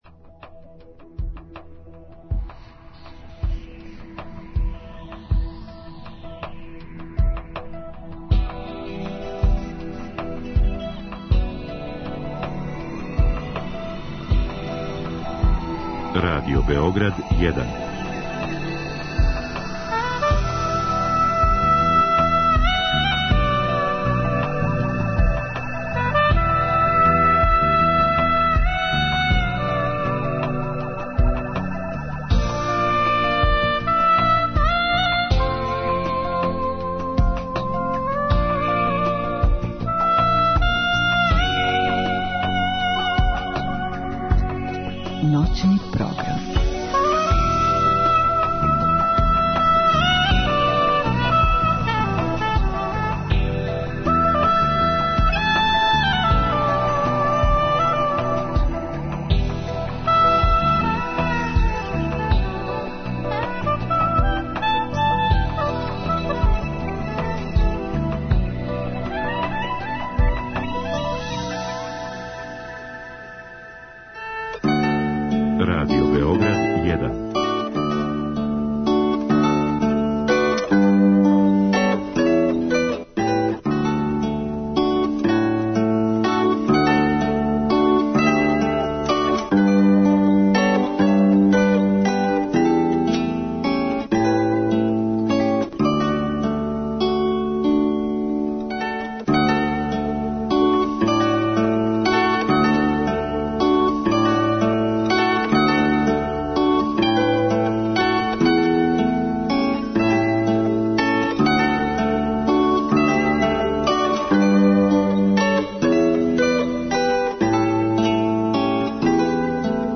Садржај емисије посвећујемо музичким фестивалима које смо пратили током лета. Многобројни гости и саговорници и овога пута обогатиће садржај наше емисије.